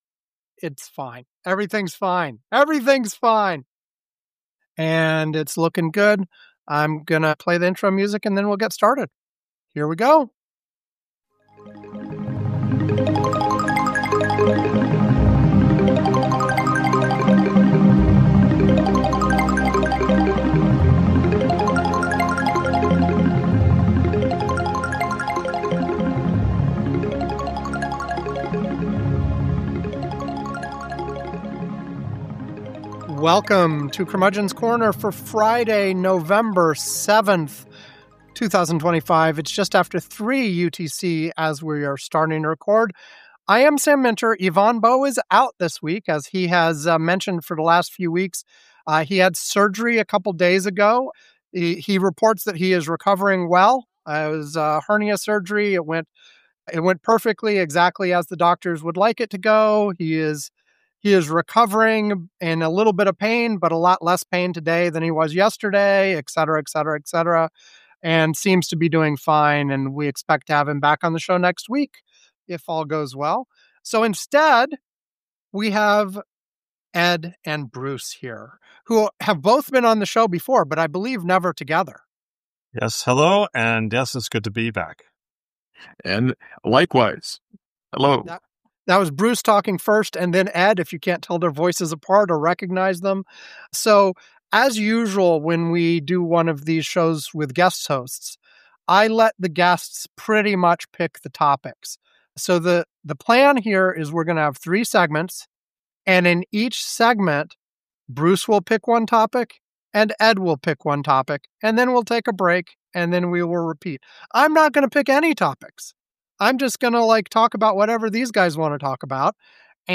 A weekly current events podcast where the hosts discuss whatever is hot in the news each week.
News Commentary